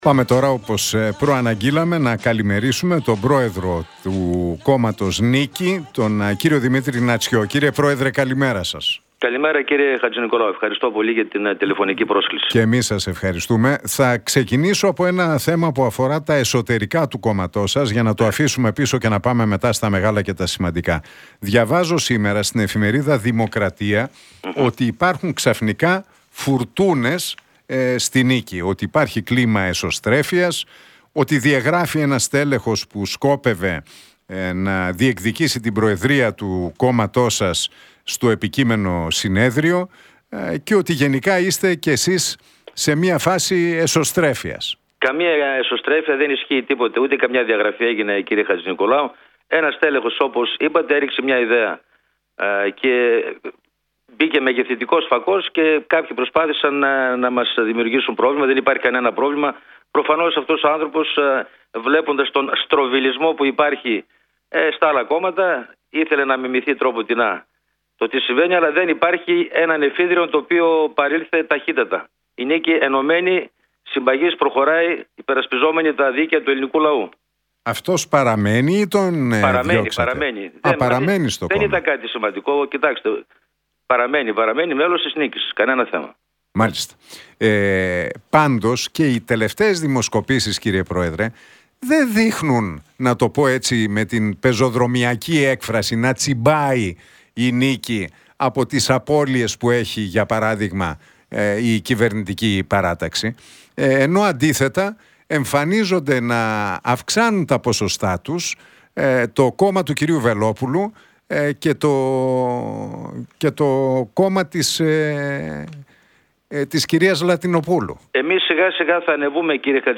Για τις εσωτερικές διεργασίες στο κόμμα του, τις δημοσκοπήσεις, την οικονομία και την ακρίβεια μίλησε ο πρόεδρος της «Νίκης», Δημήτρης Νατσιός στον Νίκο Χατζηνικολάου και τον Realfm 97,8.